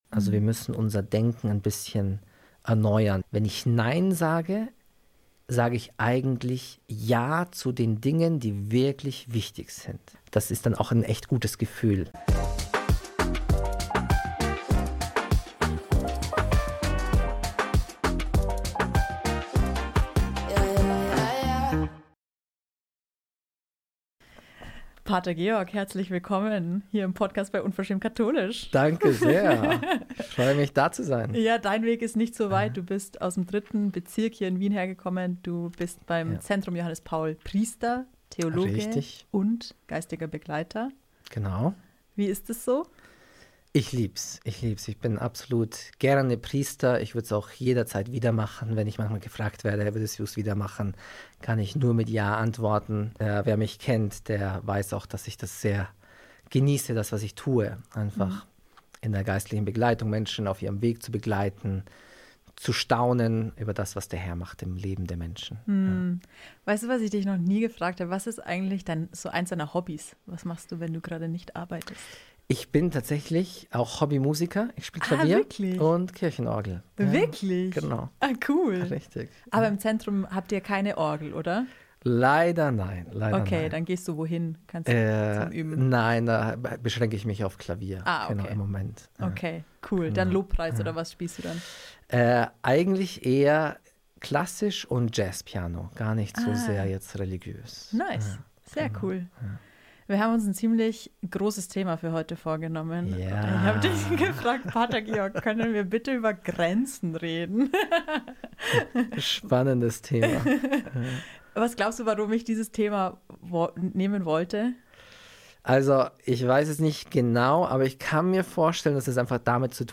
Mit ihm habe ich über ein Thema gesprochen, das viele herausfordert: Grenzen!